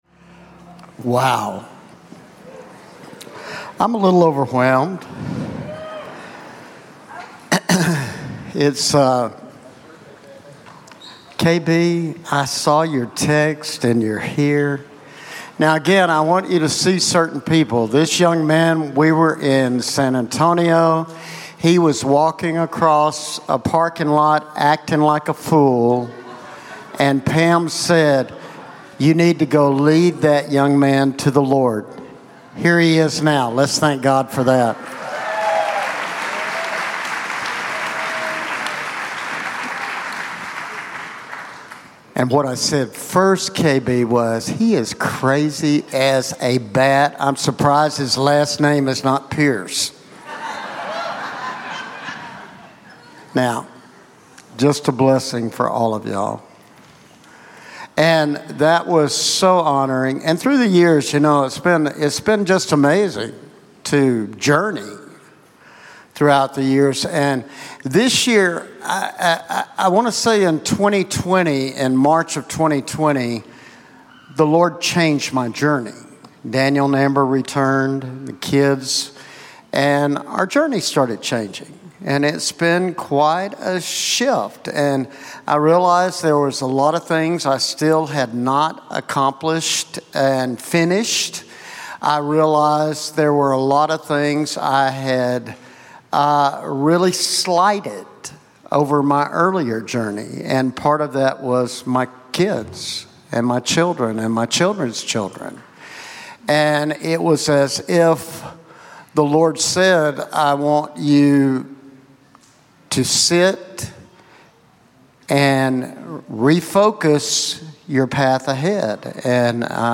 Sunday Celebration Service